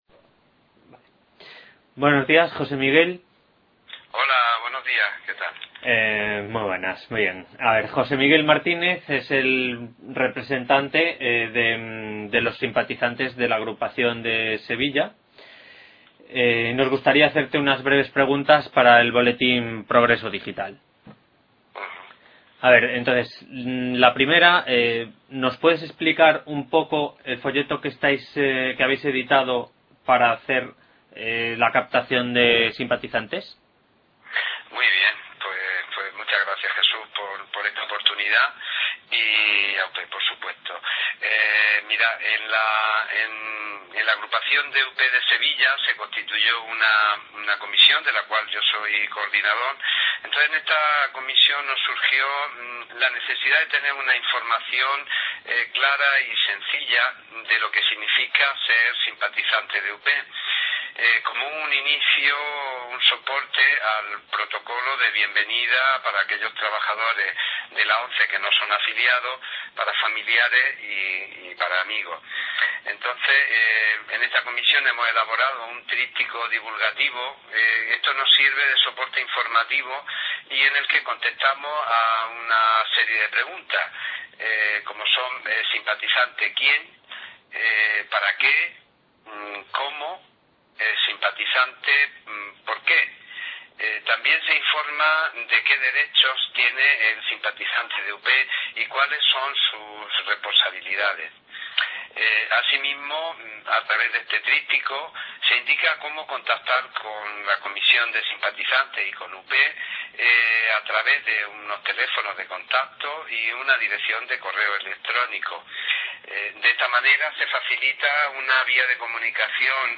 A continuación, podéis escuchar a tres simpatizantes y de su voz un resumen de dichas jornadas y de las cuestiones de futuro sobre las que quiere incidir este colectivo: